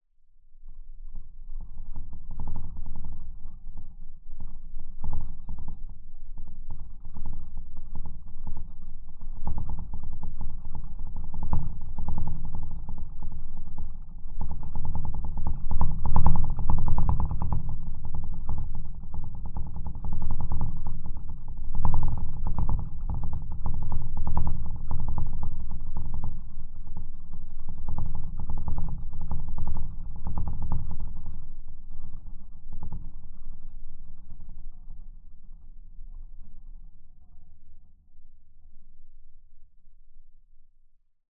Originally designed for seismic measurements, it can be used with regular field recording equipment to capture very faint vibrations in various materials and even soil.
Locks on bridge, rattling (unprocessed)
snp-bridge-locks-rattling.mp3